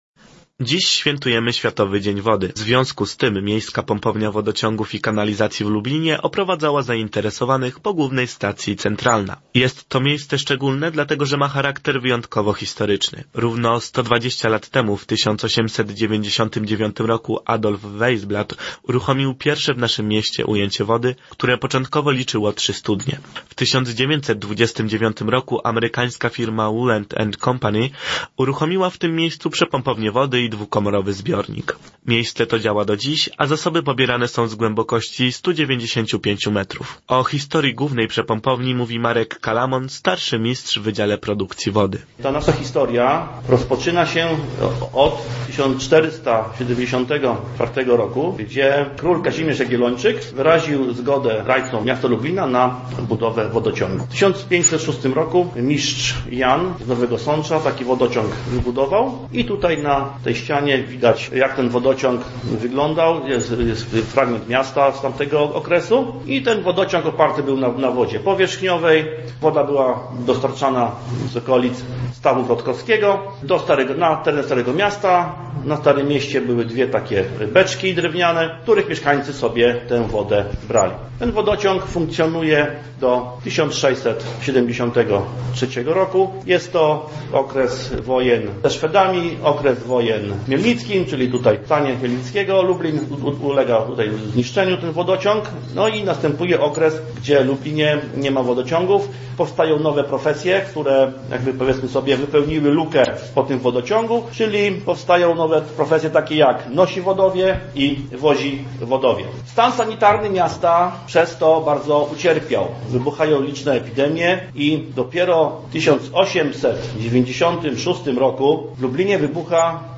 22 marca obchodzimy Światowy Dzień Wody, z tej okazji nasz reporter odwiedził dzisiaj Miejskie Przedsiębiorstwo Wodociągów i Kanalizacji w Lublinie: